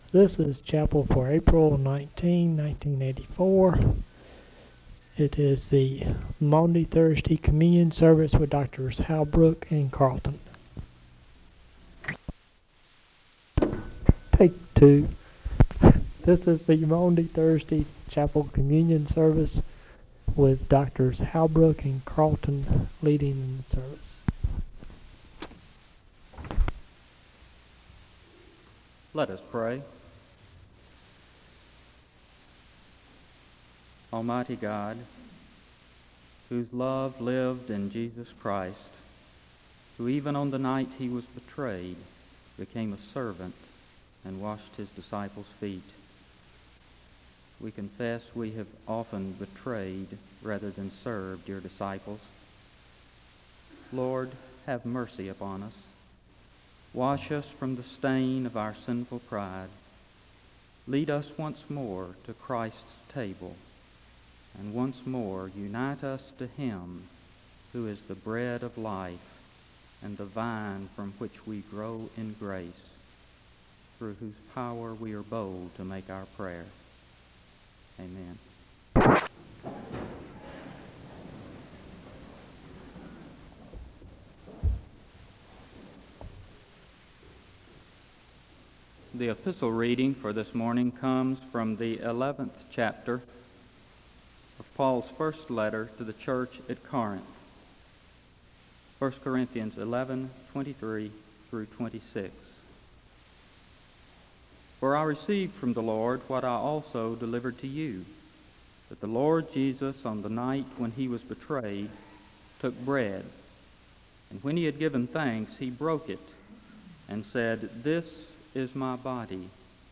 The service begins with a word of prayer (00:00-01:19).
The choir sings a song of worship (03:11-06:37).
Communion sermons